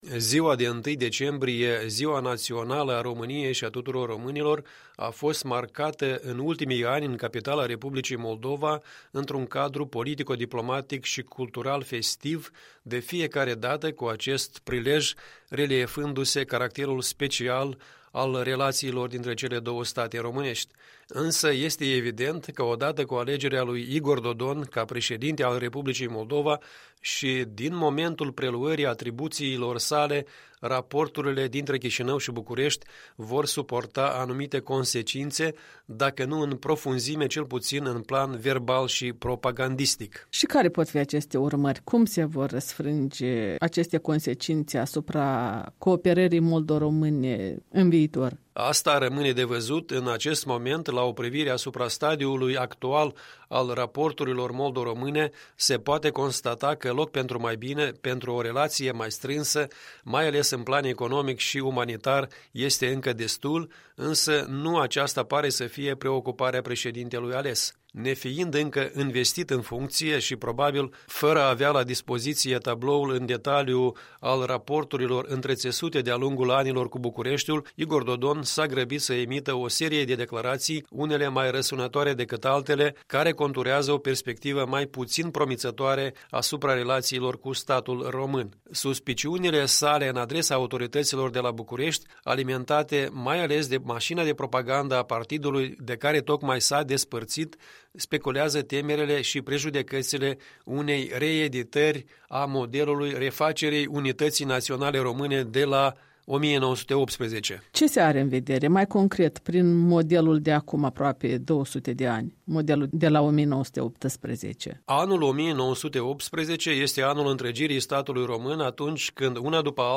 Un punct de vedere săptămânal în dialog